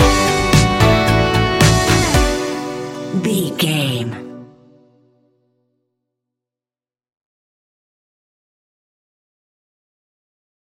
Ionian/Major
B♭
ambient
electronic
new age
chill out
downtempo
synth
pads